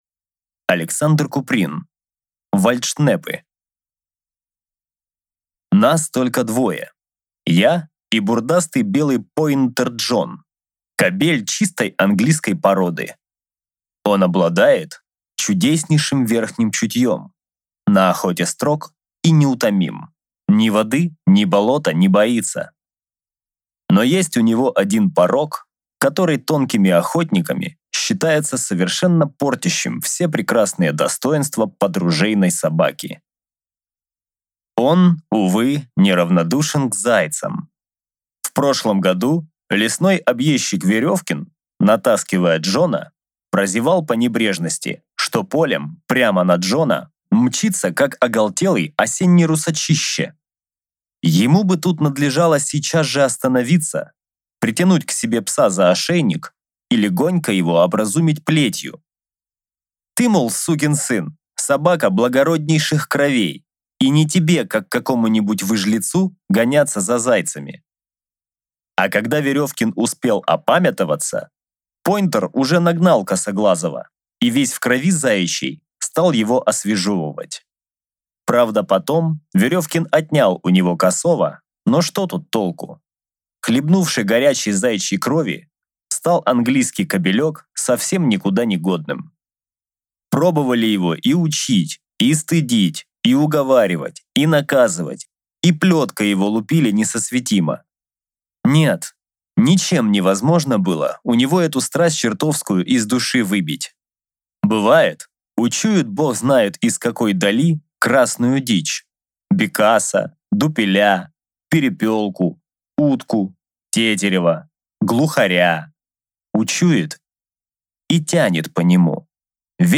Аудиокнига Вальдшнепы | Библиотека аудиокниг